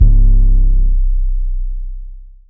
TM88 Dirty808.wav